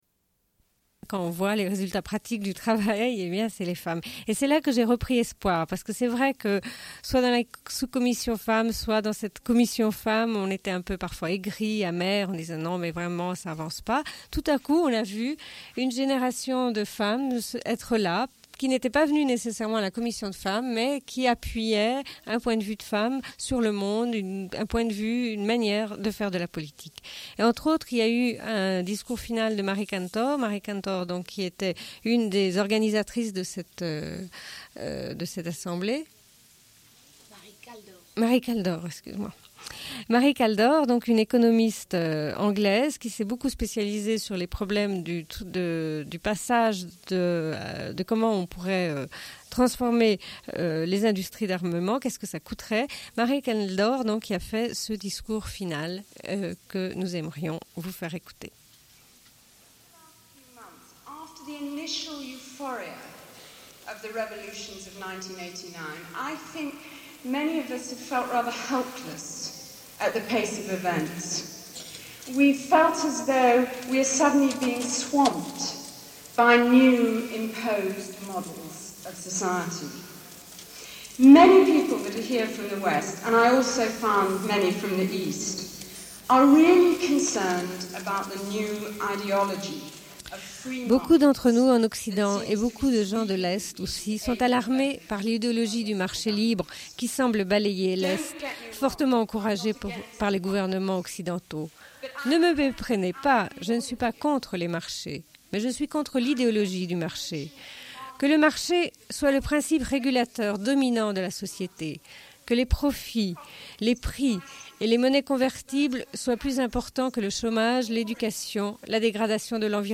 Fin de l'émission sur la Helsinki Citizens' Assembly, diffusion du discours final de l'économiste anglaise Mary Kaldor. 00:08:59
Diffusion d'un entretien.